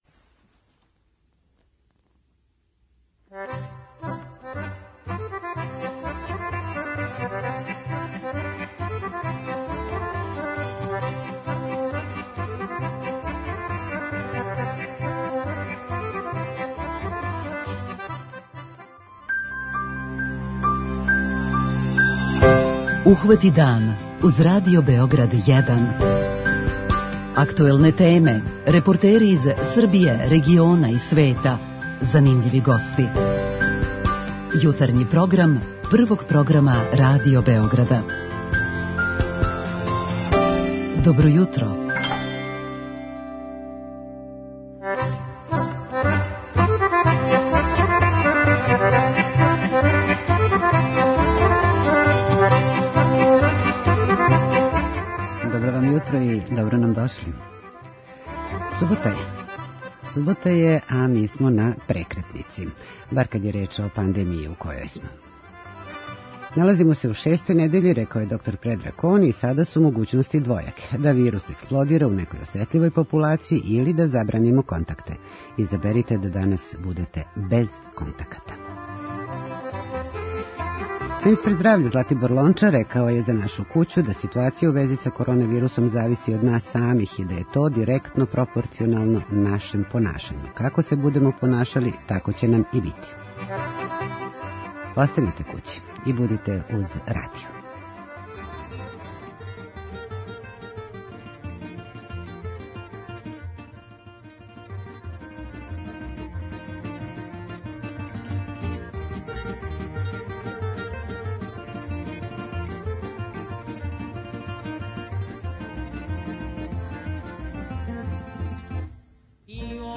Danas je Dan Novog Beograda - o vremenu od pre 72 godine, kada je počela izgradnja, razgovaramo sa graditeljem i stanovnikom Novog Beograda.